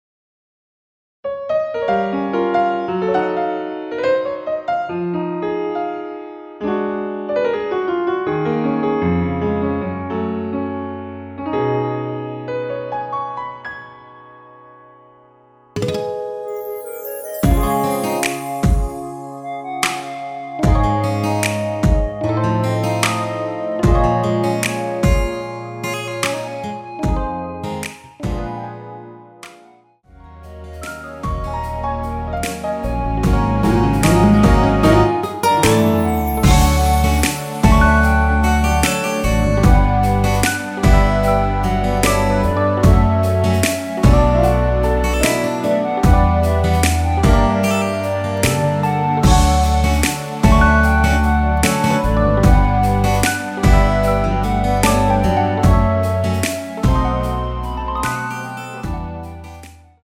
원키에서(-2)내린 멜로디 포함된 MR입니다.
Db
앞부분30초, 뒷부분30초씩 편집해서 올려 드리고 있습니다.
중간에 음이 끈어지고 다시 나오는 이유는